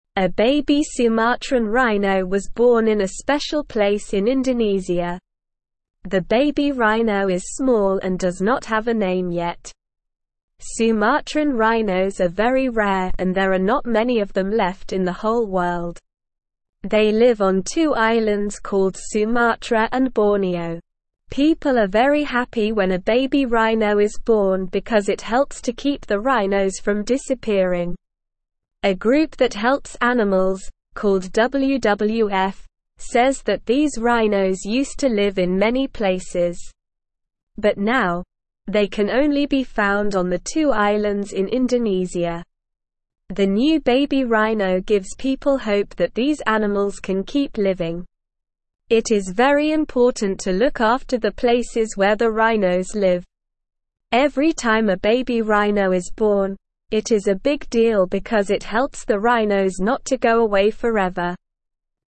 English-Newsroom-Beginner-SLOW-Reading-Baby-Sumatran-Rhino-Brings-Hope-for-Rare-Animals.mp3